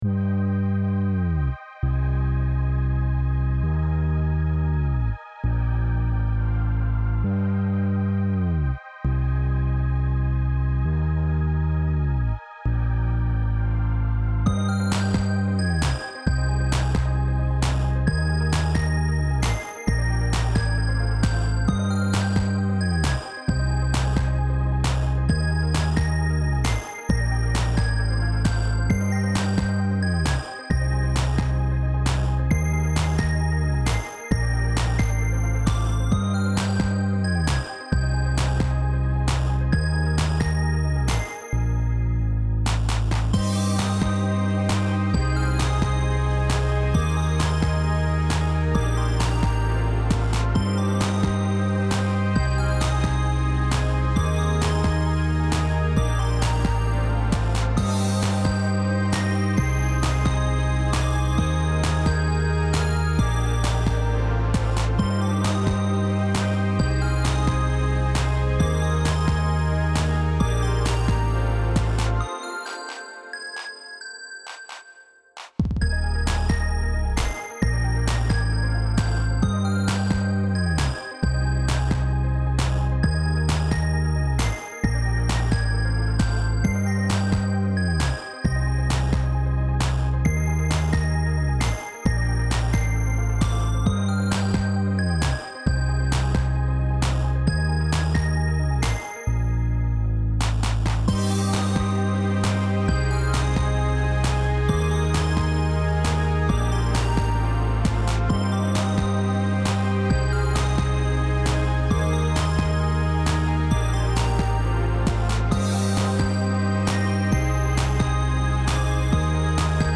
Style Style EDM/Electronic
Mood Mood Cool, Relaxed
Featured Featured Bass, Drums, Synth
BPM BPM 133